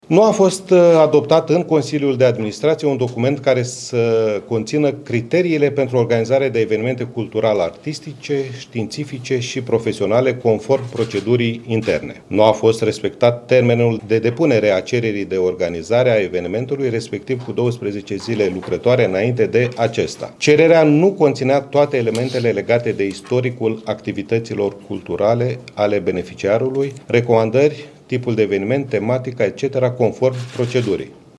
Ministrul a arătat că nu a fost respectată nici durata evenimentului, lucru care ar fi necesitat încheierea unui act adițional la contractul inițial: